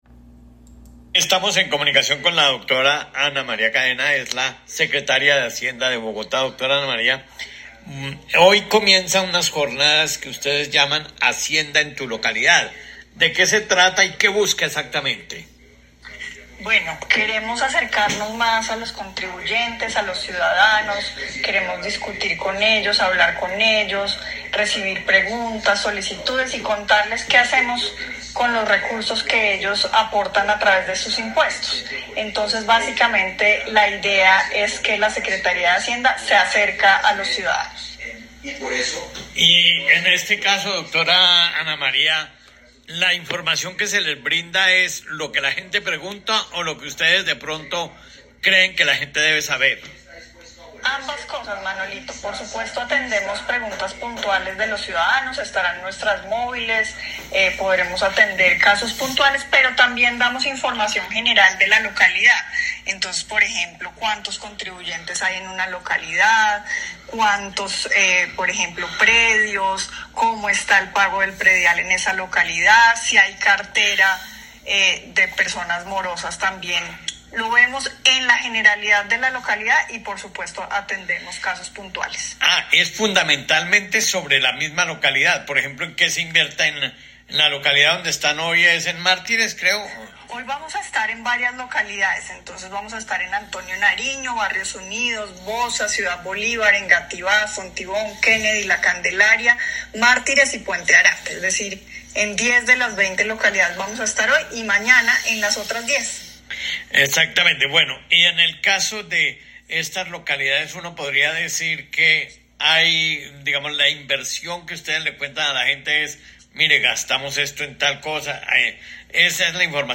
Entrevista Minuto de Dios.mp3